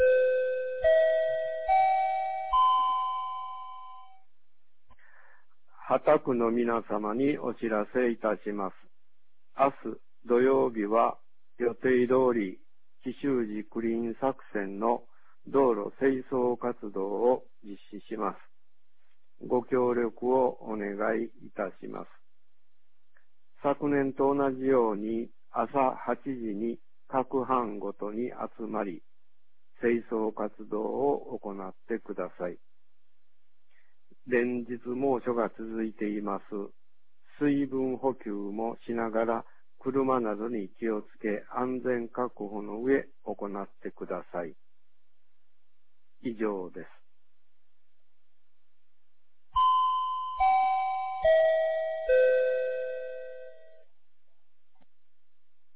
2020年08月21日 18時31分に、由良町から畑地区へ放送がありました。